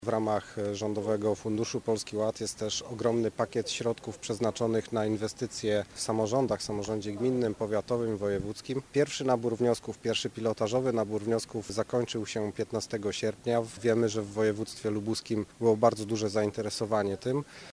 Na zielonogórskim deptaku prezentowali założenia Polskiego Ładu
Prezentacja założeń i dokonań w ramach programu Polski Ład, była tematem konferencji prasowej polityków Prawa i Sprawiedliwości.